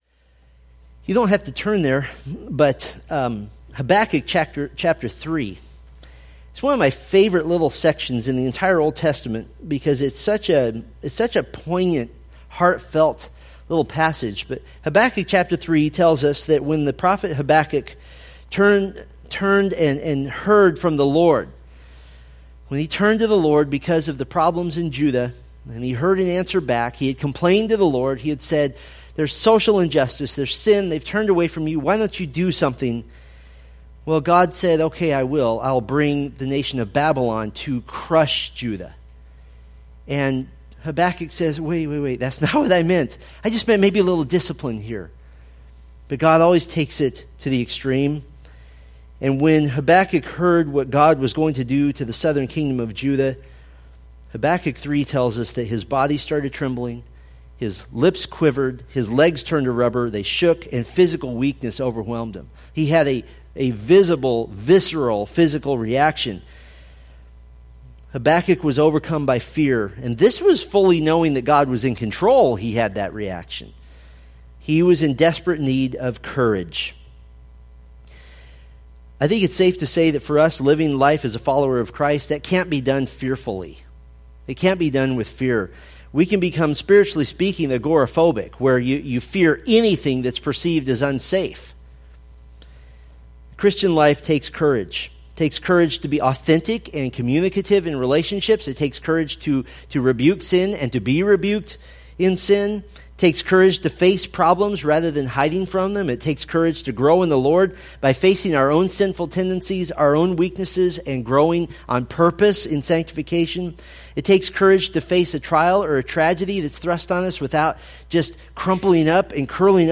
Preached October 16, 2016 from Isaiah 36:1-37:7